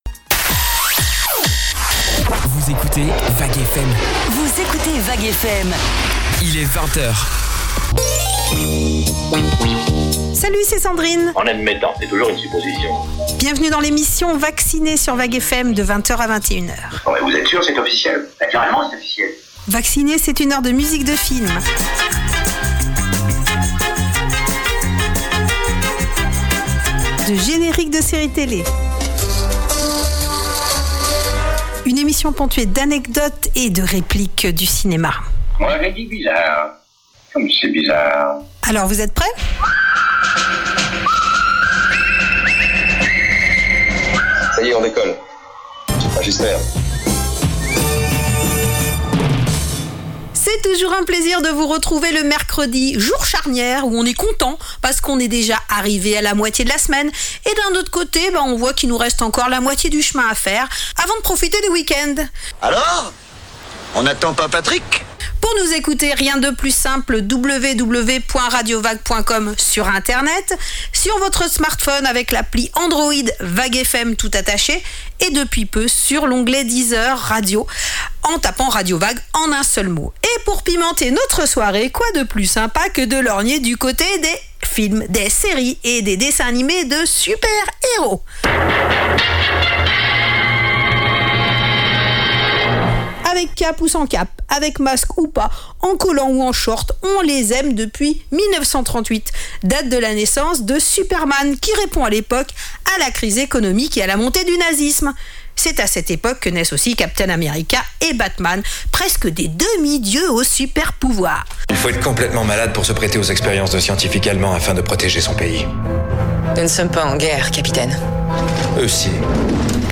Retrouvez dans l'émisssion consacrée aux musiques de films et génériques de séries Télé et dessins animés les plus beaux scores des Marvel, des DC Comics, et d'autres franchises !